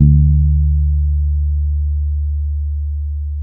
-MM DUB  D 3.wav